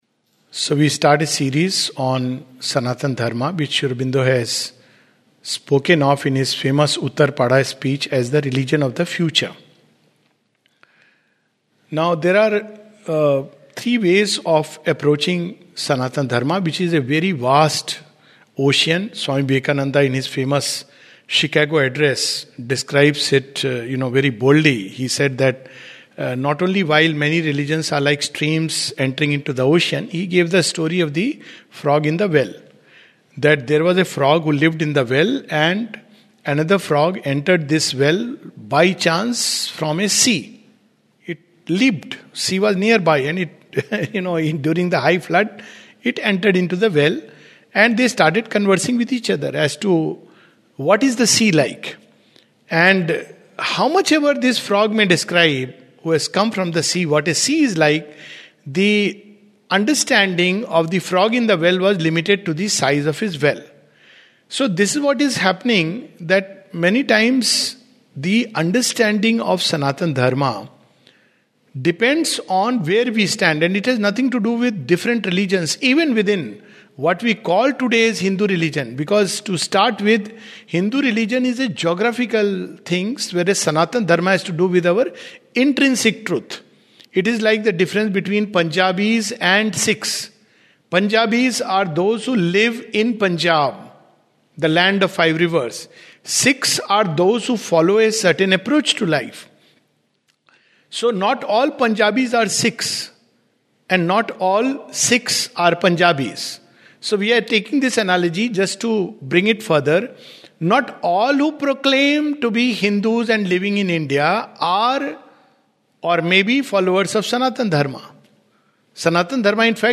This is the first of a series of talks on Sanatana Dharma, the Eternal Religion.